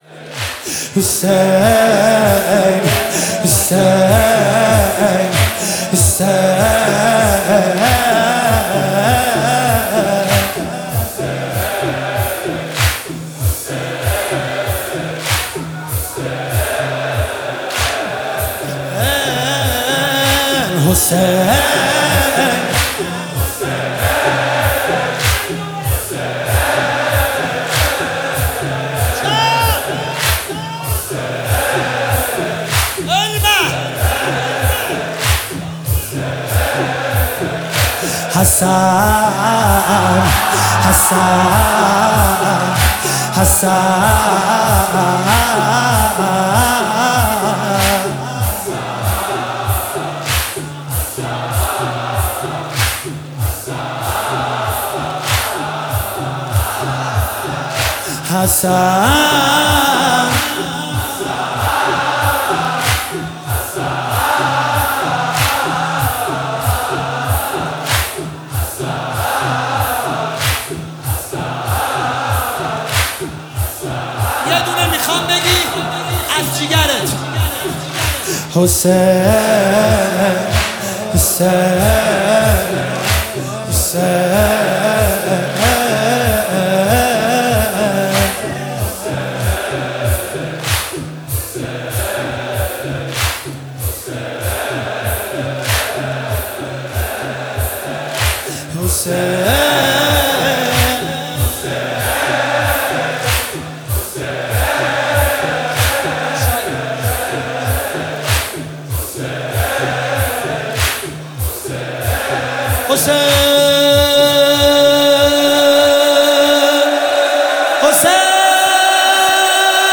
محفل زوارالبقیع طهران
فاطمیه دوم 1403